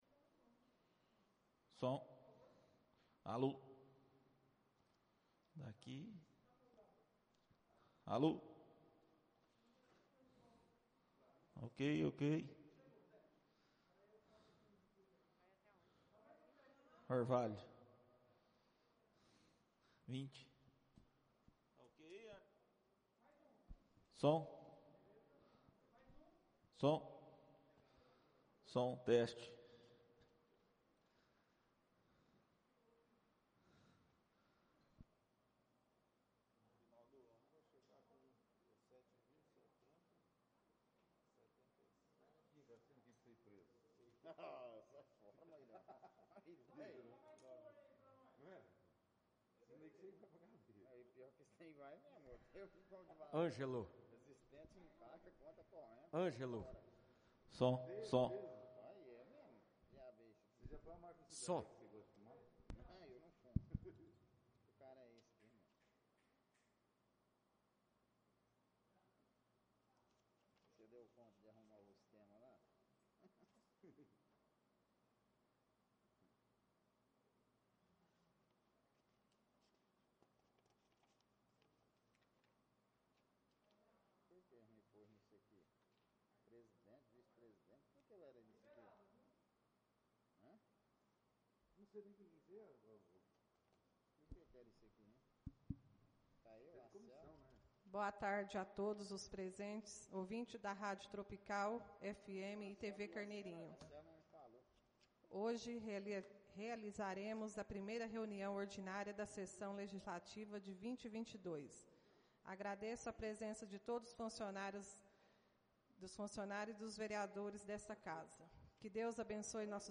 Áudio da 1.ª reunião ordinária de 2022, realizada no dia 07 de Fevereiro de 2022, na sala de sessões da Câmara Municipal de Carneirinho, Estado de Minas Gerais.